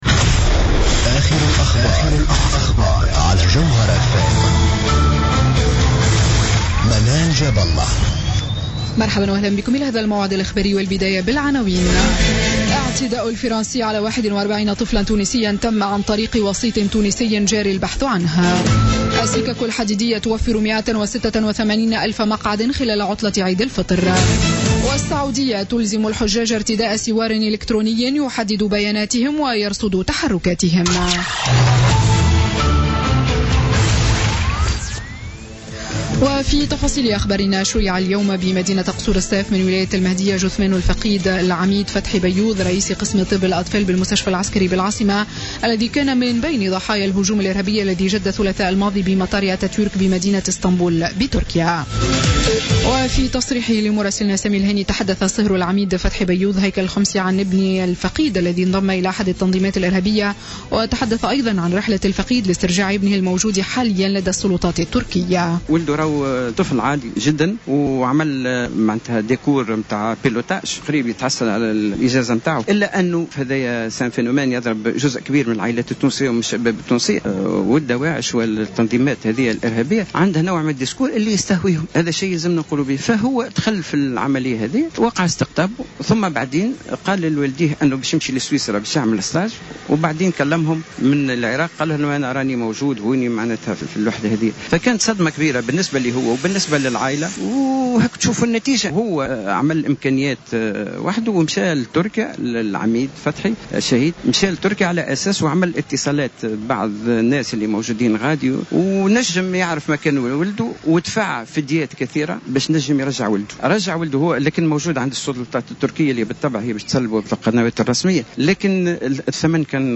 نشرة أخبار الخامسة مساء ليوم الجمعة 1 جويلية 2016